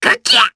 Lakrak-Vox_Damage_jp_03.wav